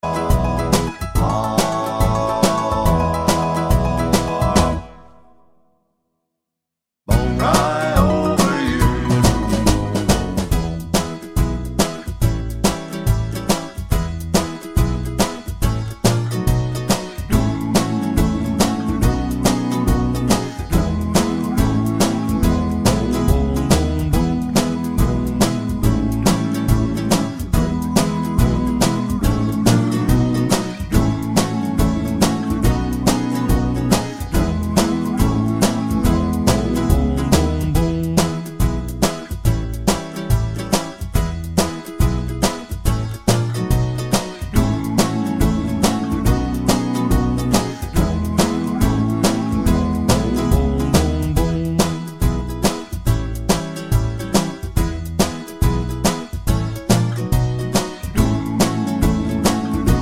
no Backing Vocals with whistle Pop (1950s) 2:25 Buy £1.50